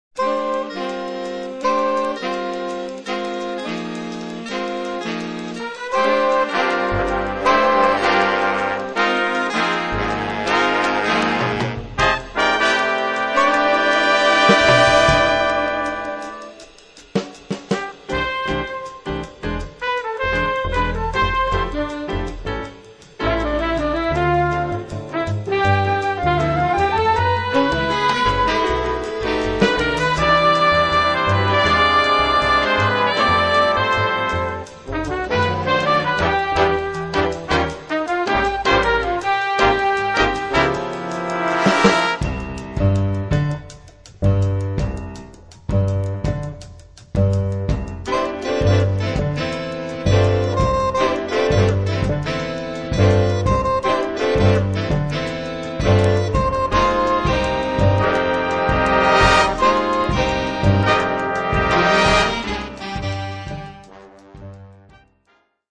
registrato dal vivo il 18 Luglio 2008